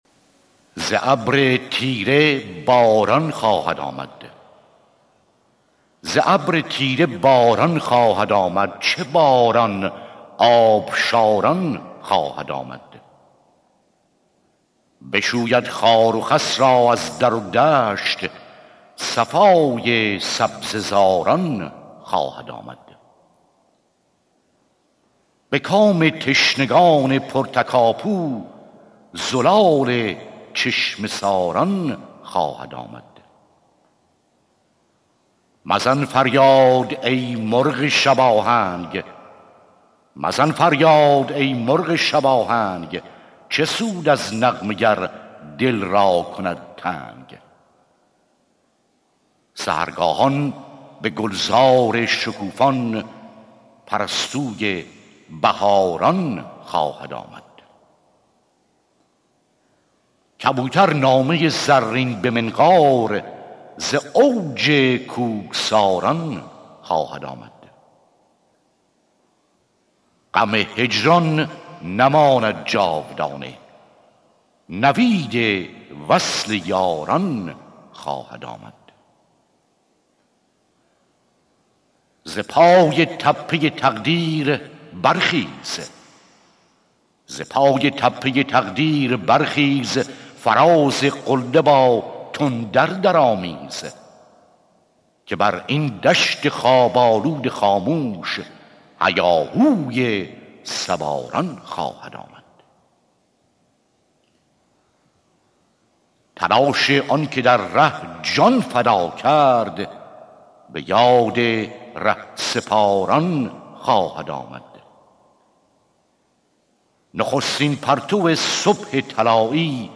سرود - شماره 5 | تعالیم و عقاید آئین بهائی